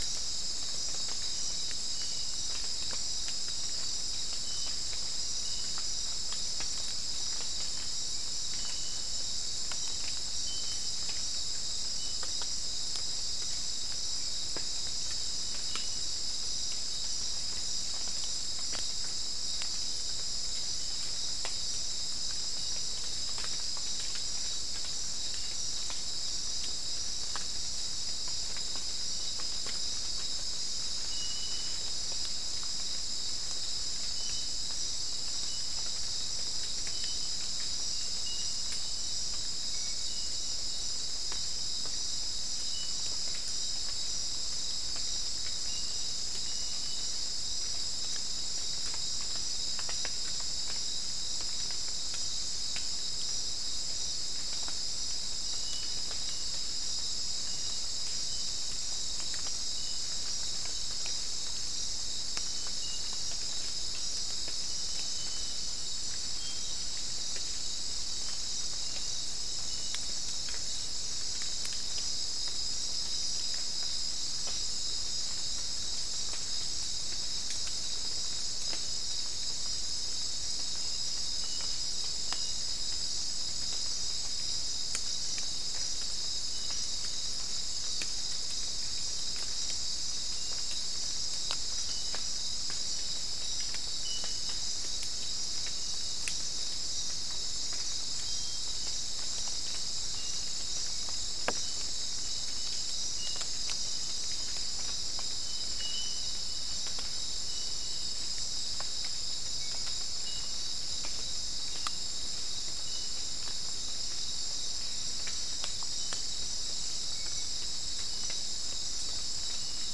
Soundscape
Location: South America: Guyana: Kabocalli: 3
Recorder: SM3